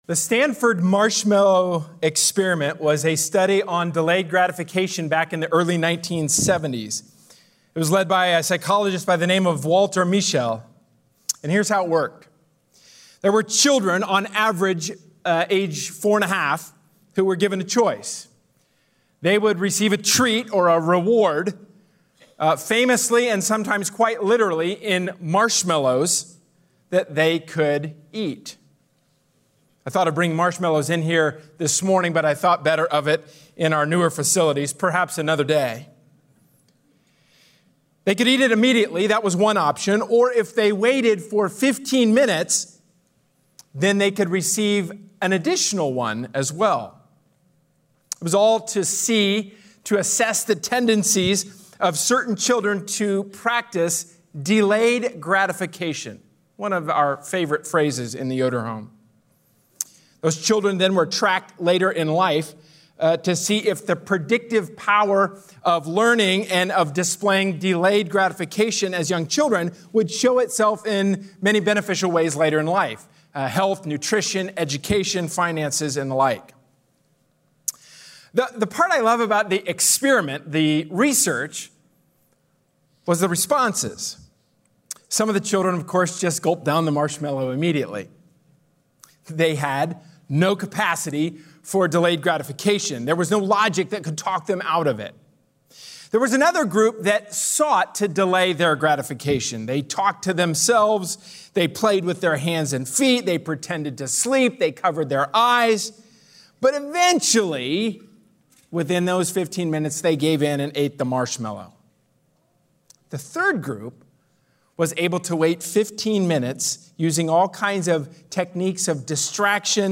A sermon from the series "James: Faith/Works."